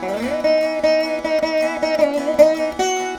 150  VEENA.wav